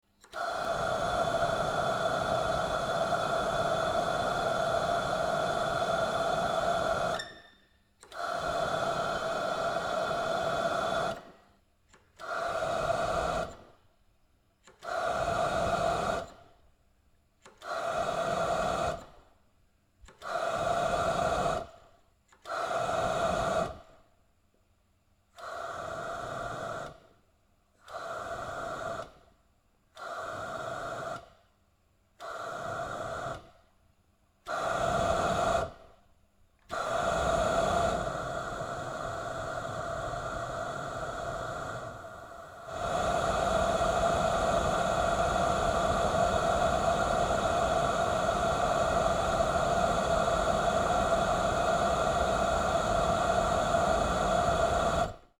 Шум работающего расходомера кислорода в больнице